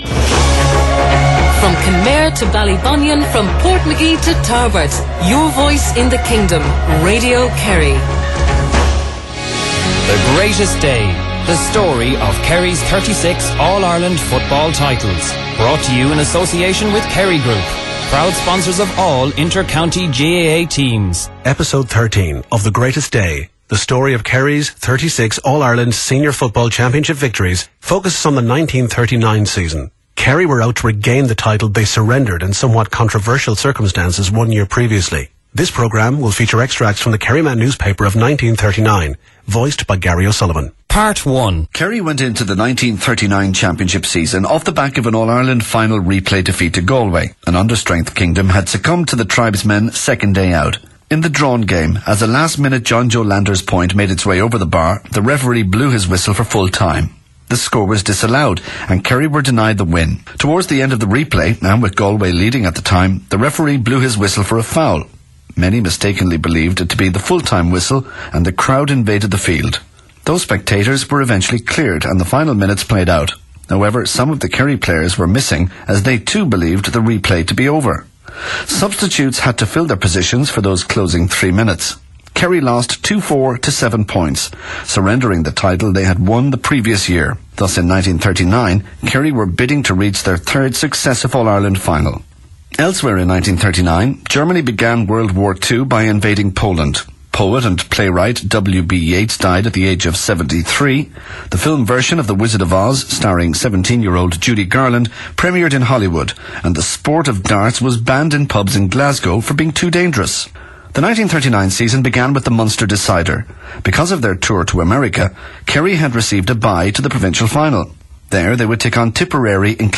The Greatest Day Back to The Greatest Day The Greatest Day - AI Title No. 13 - 1939 Recorded: 2009 Length: 43mins play pause stop min volume max volume A 36 part series documenting the story of Kerry's All Ireland Senior Football Championship victories.